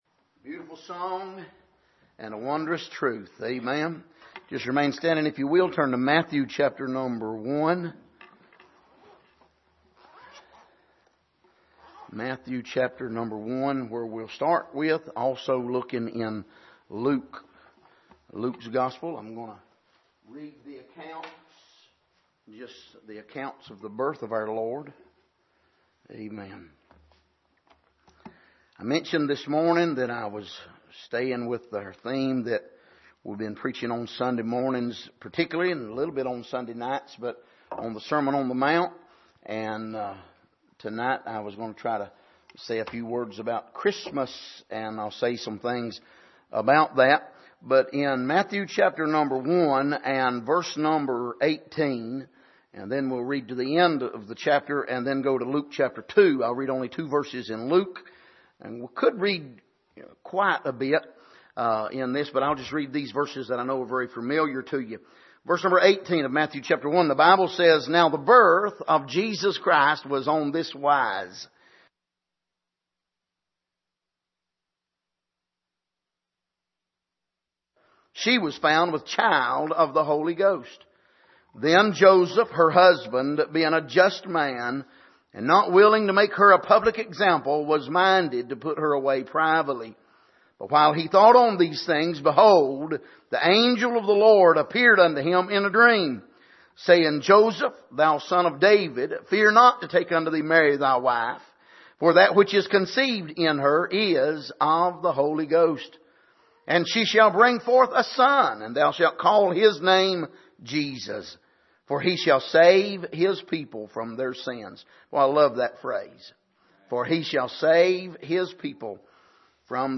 Passage: Matthew 1:18-25 Service: Sunday Evening